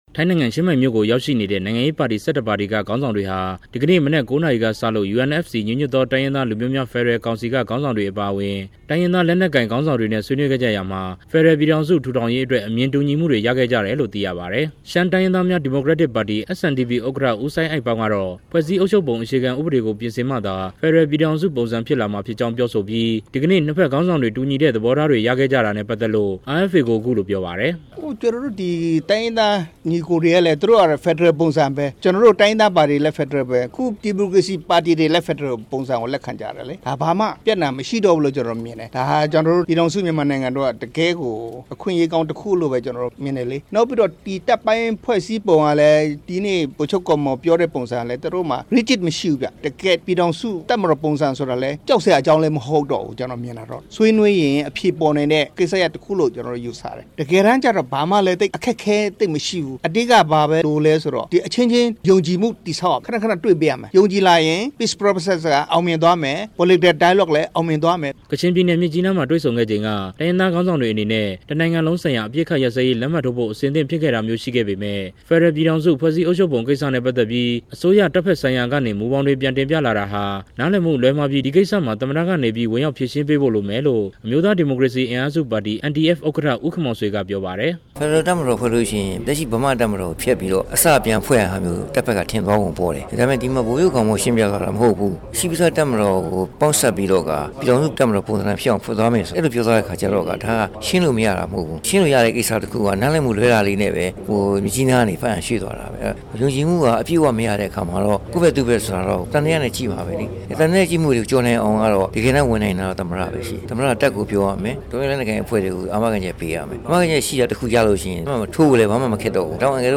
ဆွေးနွေးပွဲ သတင်းပေးပို့ချက်